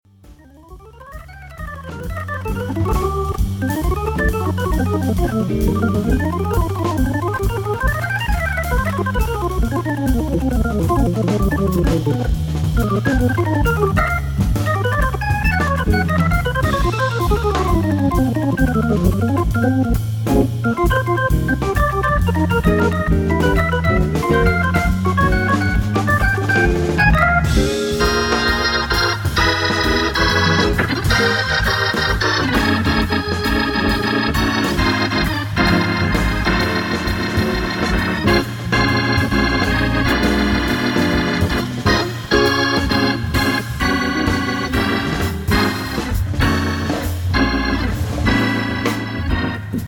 full drawbar ahead !!!
drums